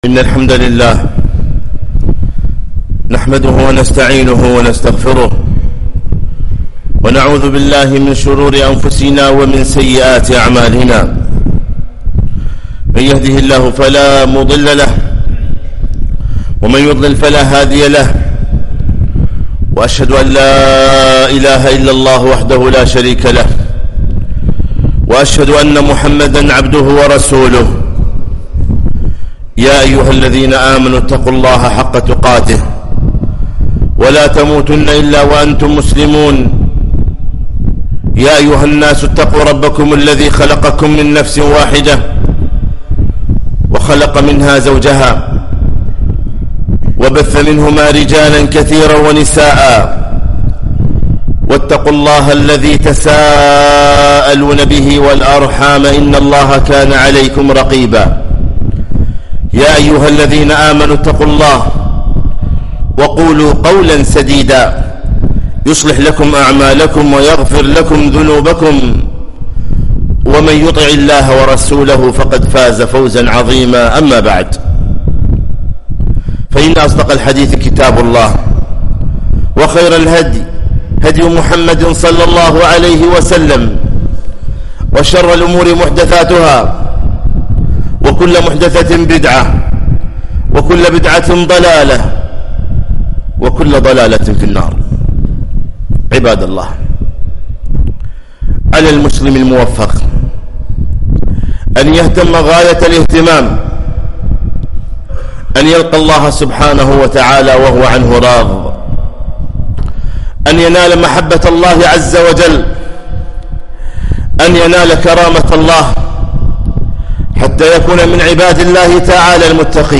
خطبة - (اهتم بعقيدتك)